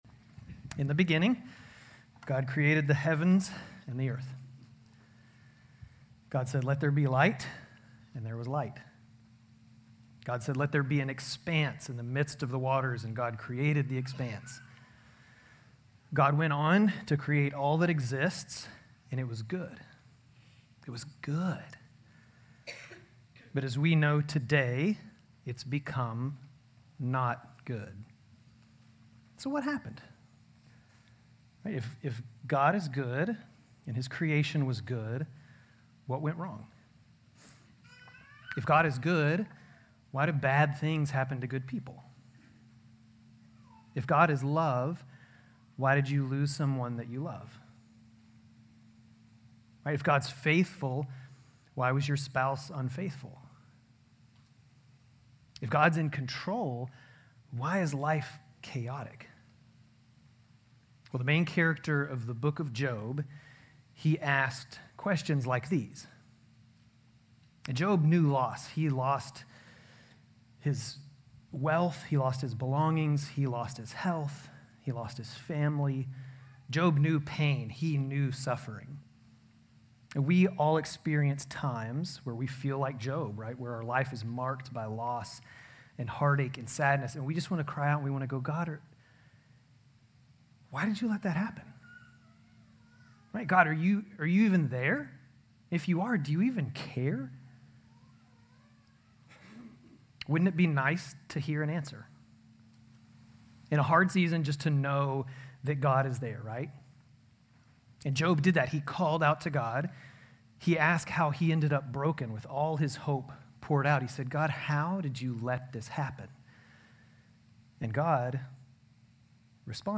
GCC-Lindale-September-17-Sermon.mp3